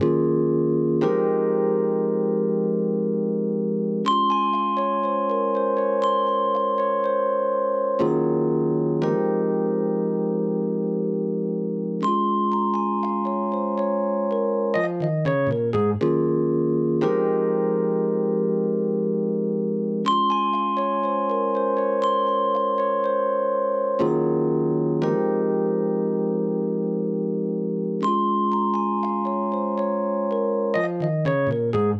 13 rhodes A2.wav